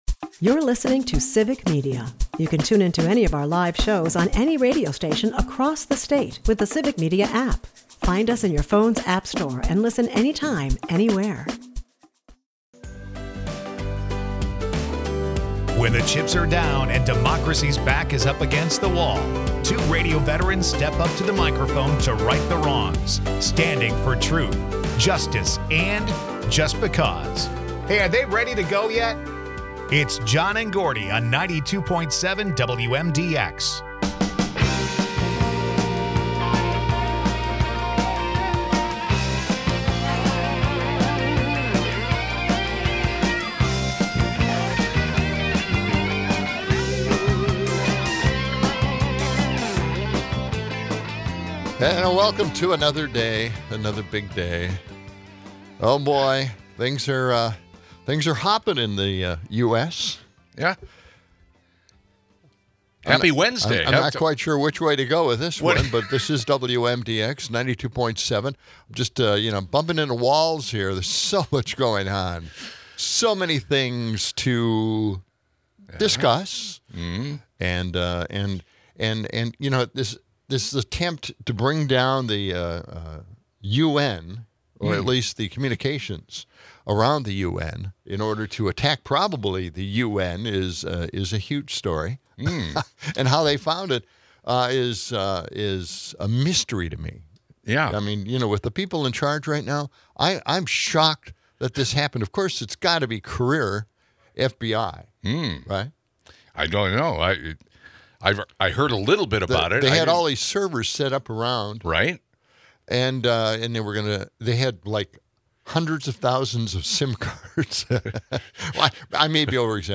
All this, alongside playful banter and existential ponderings about rapture warnings and facing fears, makes for an eclectic mix.